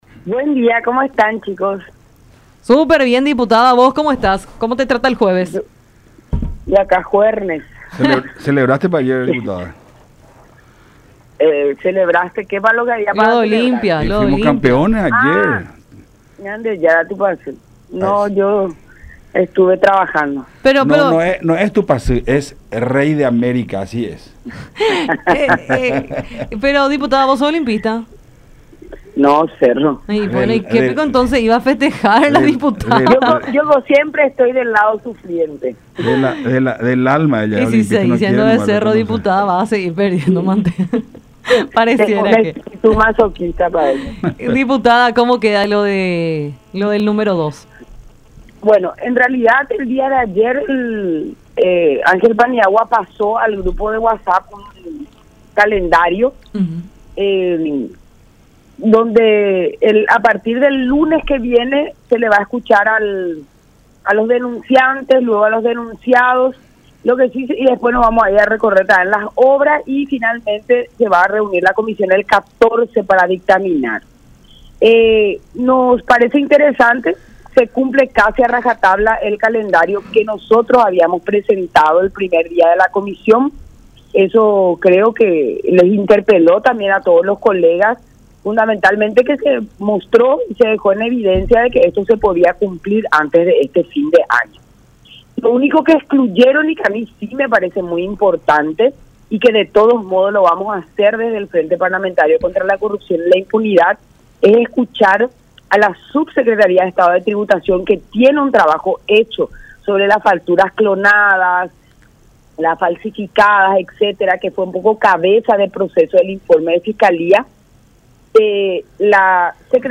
Entonces, se demuestra que esto se podía cumplir antes del fin de este año”, resaltó González en conversación con Enfoque 800 por La Unión.